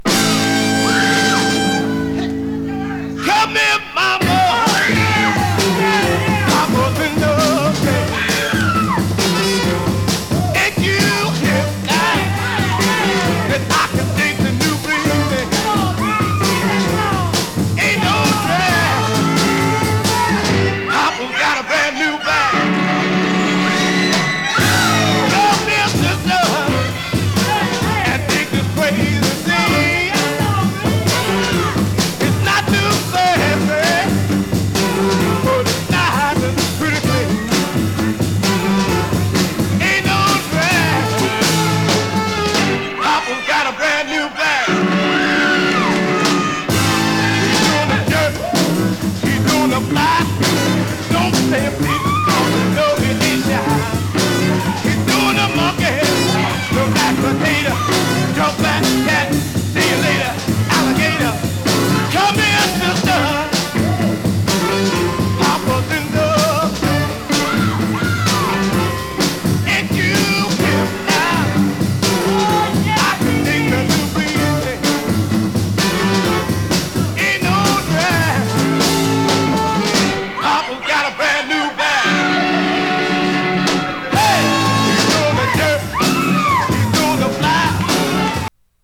熱狂の坩堝という観客の盛り上がりを封じ込めたライヴ・アルバムです！＊音の薄い部分でチリチリ・ノイズ。時折パチ・ノイズ。